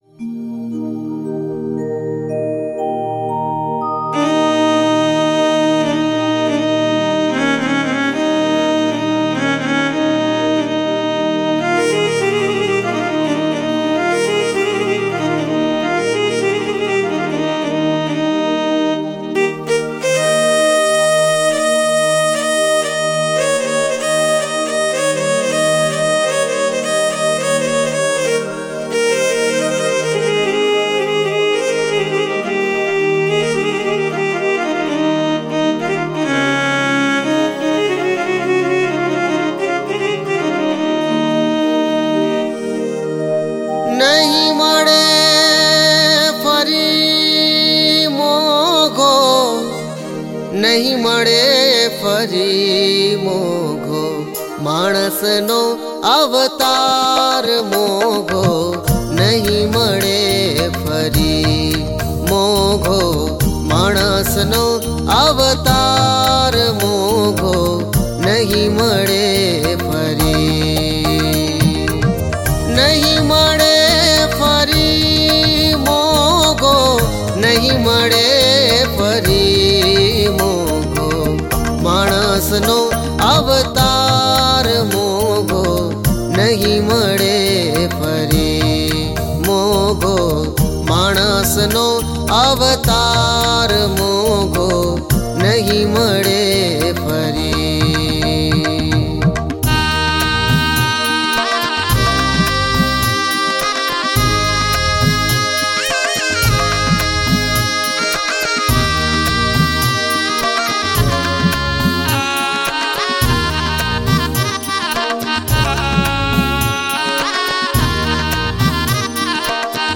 તાર રાગ : મિશ્ર ખમાજ (પદ: )